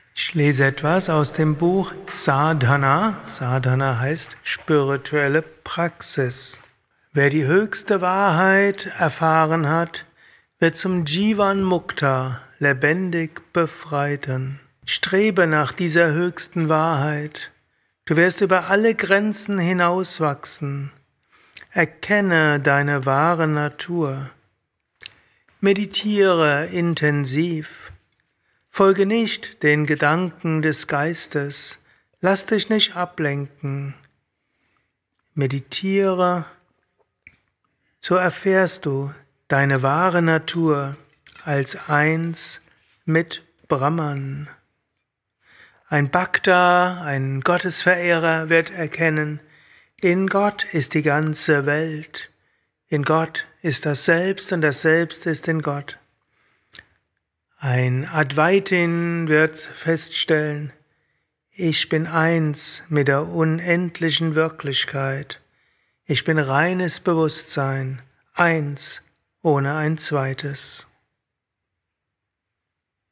Vidya, eine Aufnahme während eines Satsangs gehalten nach einer